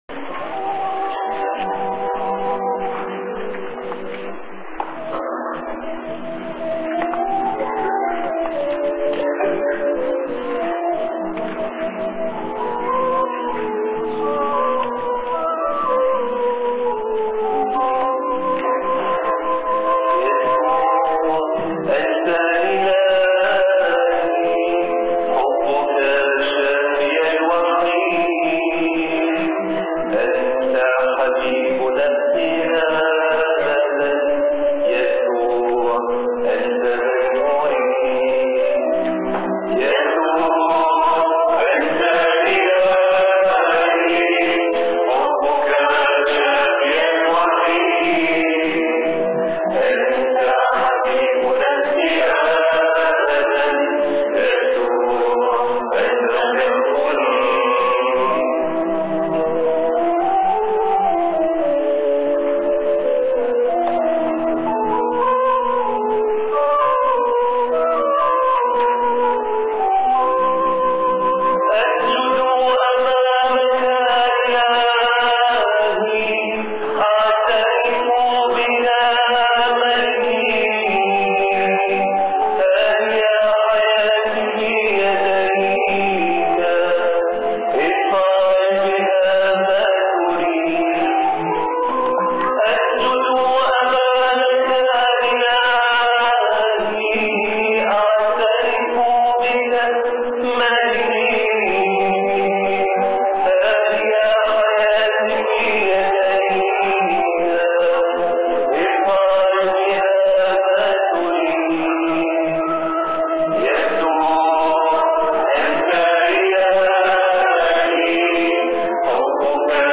-Audio Recording of the Qoorbono